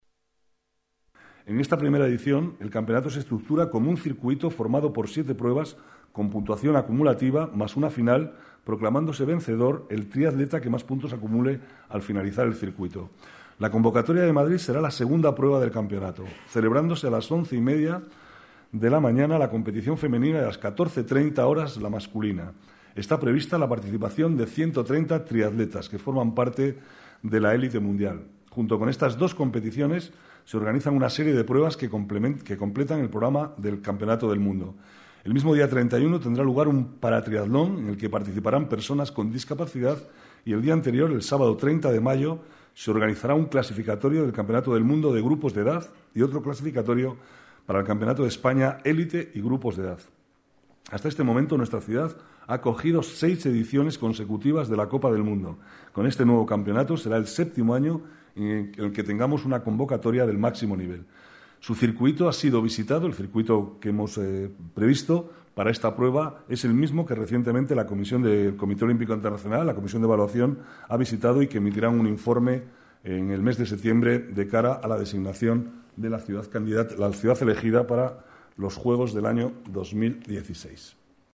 Nueva ventana:Declaraciones del vicealcalde, Manuel Cobo, sobre el Campeonato del Mundo de Triatlón